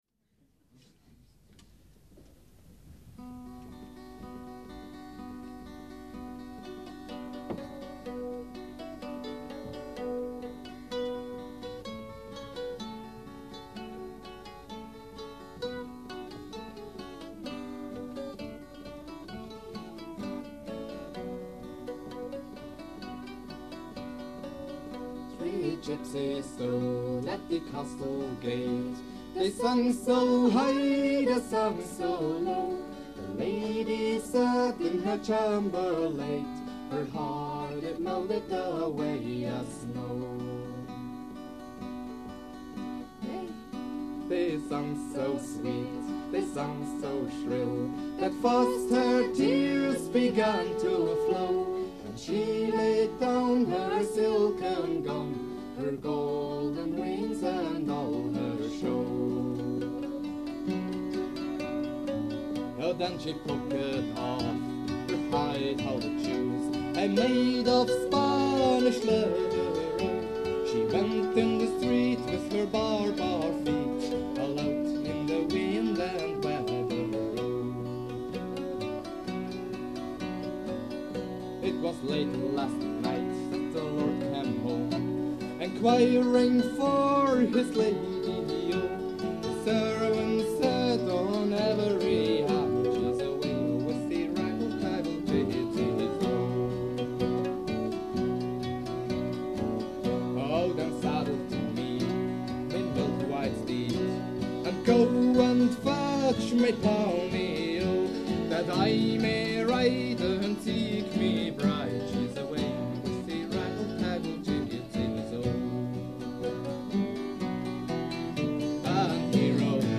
Remastered 2010